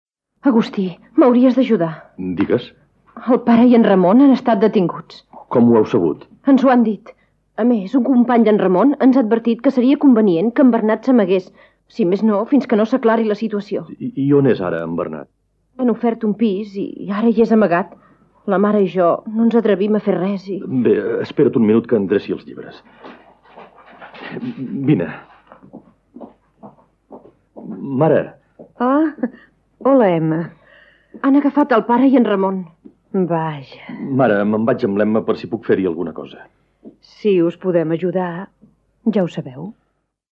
Fragment dialogat de l'adaptació radiofònica de l'obra "Aquell gust agre de l'estel", de Robert Saladrigas
Ficció